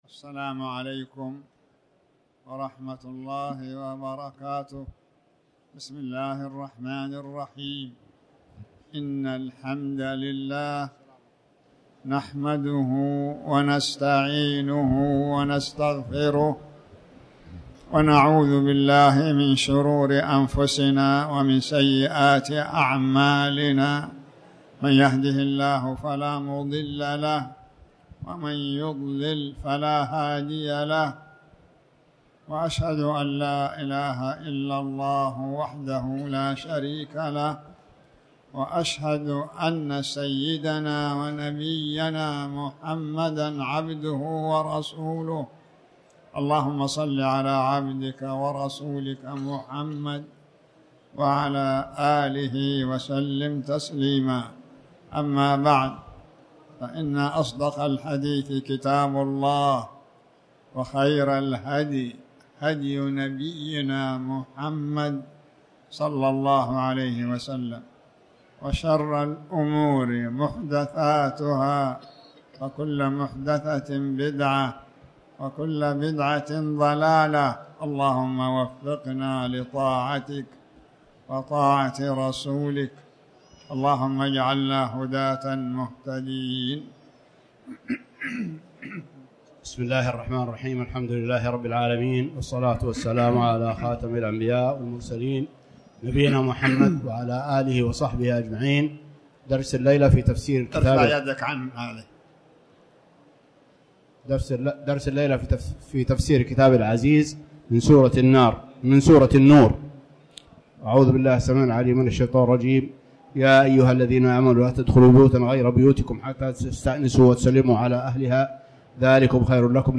تاريخ النشر ٥ ربيع الثاني ١٤٤٠ هـ المكان: المسجد الحرام الشيخ